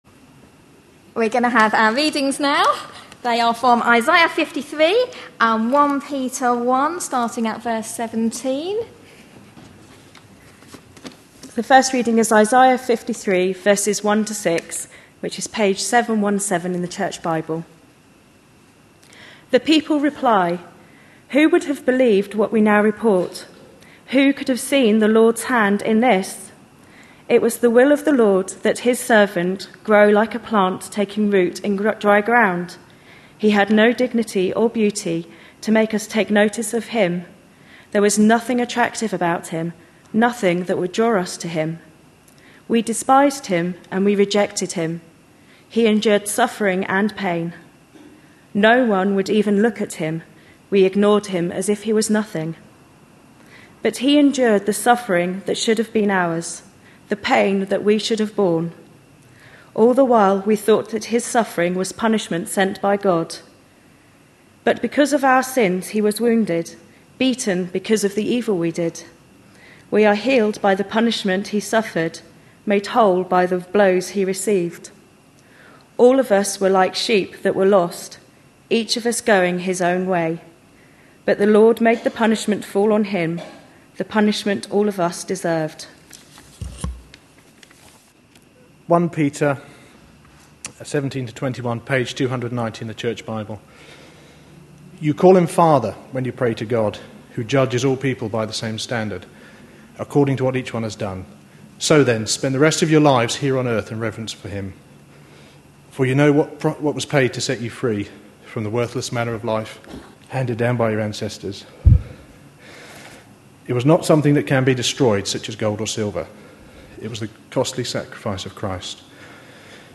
| Sermons | Central Baptist Church, Chelmsford